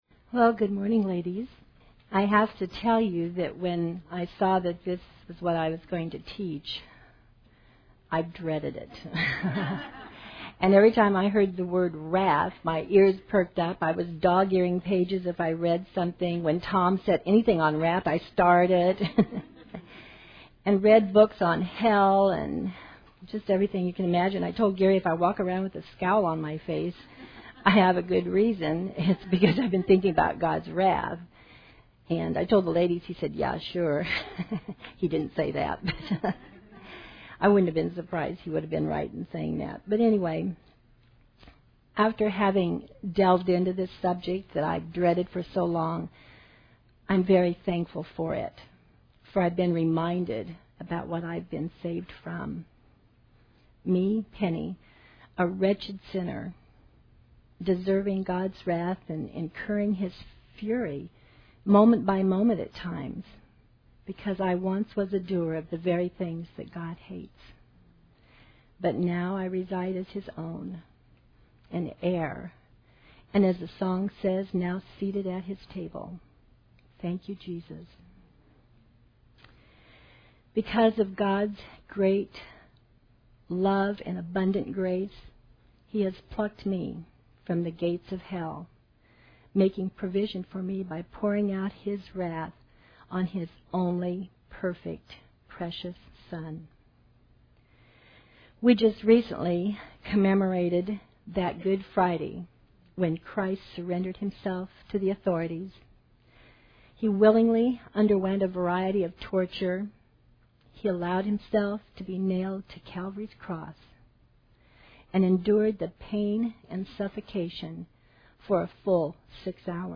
Women Women - Bible Study - The Attributes of God Audio ◀ Prev Series List Next ▶ Previous 16.